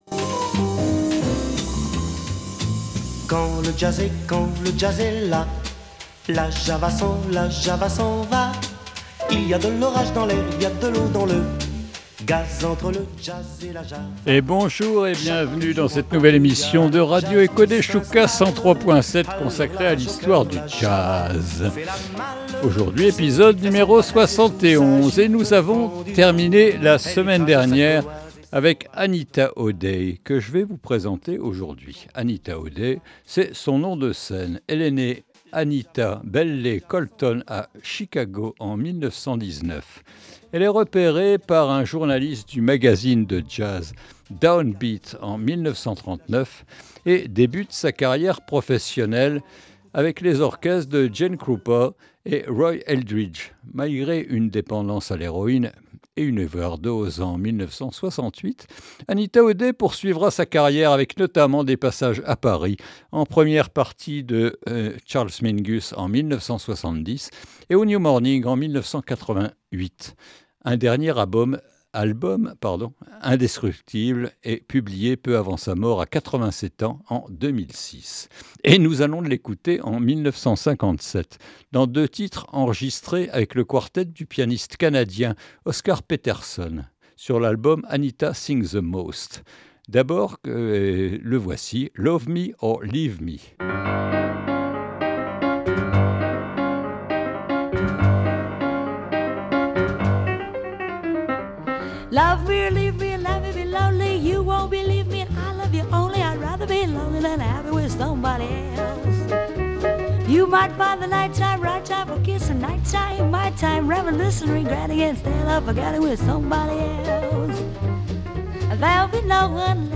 Quand le jazz est là est une nouvelle émission consacrée à l’histoire du jazz.